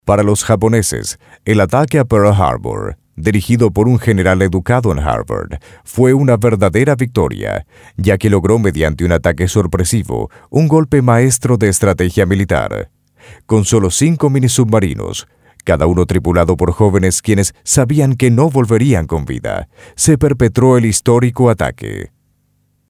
LOCUTOR PREMIUM
OBSERVACIONES: Voz versátil para todo tipo de grabaciones en diversos idiomas.
DEMO NARRACION: